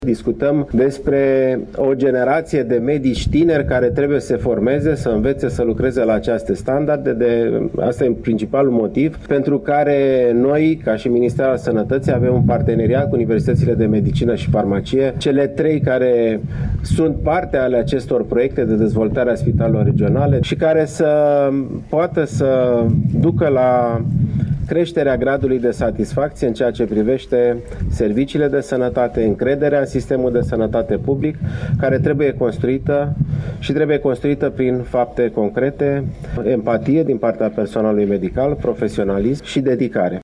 La Iași, au început astăzi lucrările la viitorul Spital Regional de Urgență, în prezența ministrului Sănătății, Alexandru Rafila.
19-aprilieora-14-Alexandru-Rafila.mp3